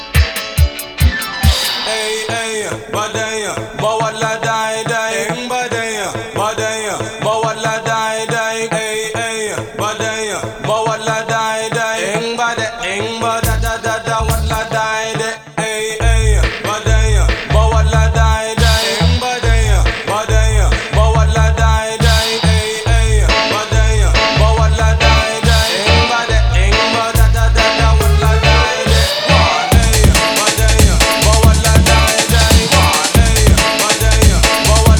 Жанр: Пост-хардкор / Хард-рок